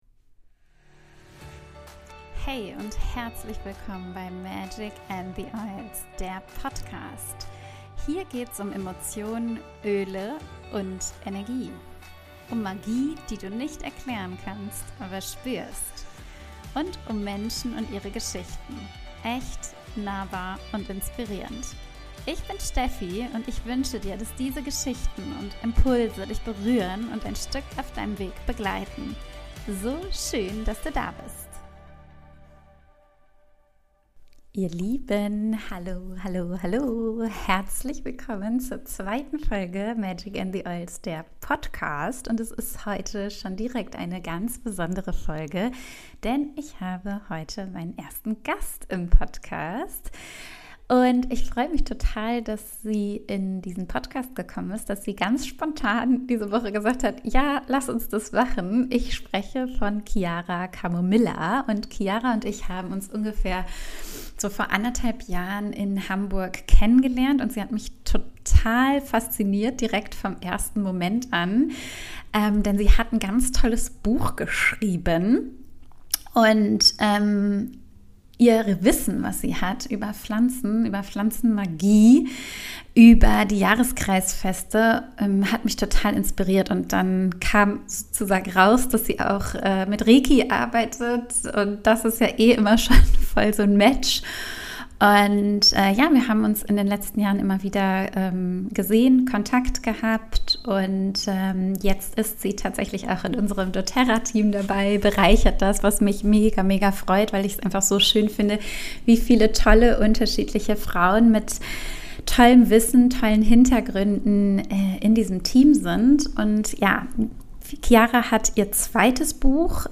Ein Gespräch über Dankbarkeit, Wandel und die kleinen (Alltags-)Momente voller Magie.